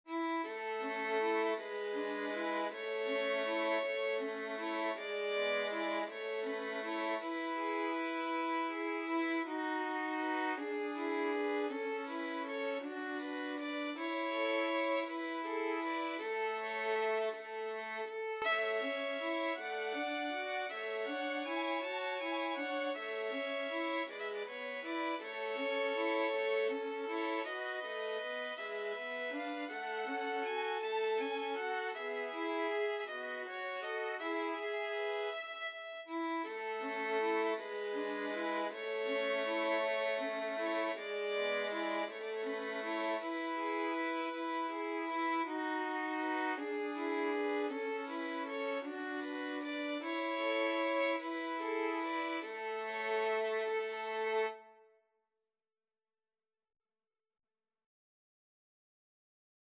Violin 1Violin 2Violin 3
6/8 (View more 6/8 Music)
Andante
Pop (View more Pop Violin Trio Music)